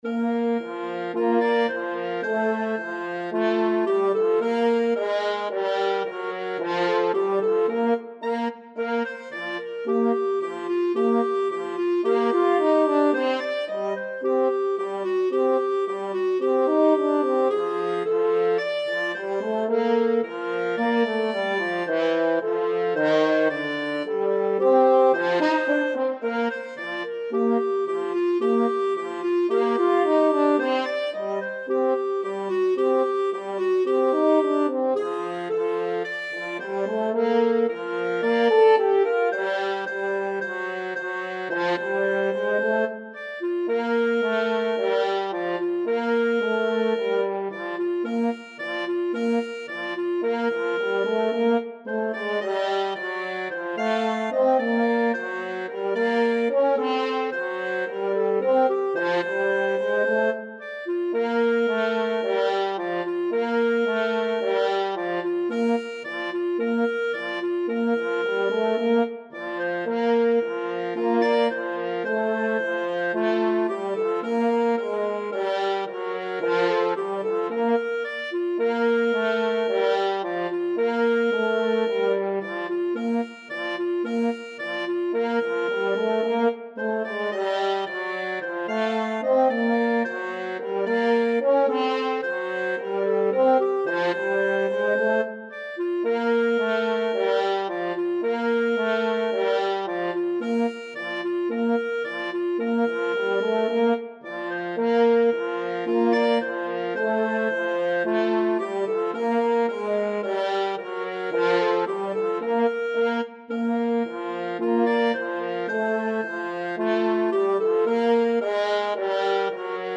2/4 (View more 2/4 Music)
Moderato allegro =110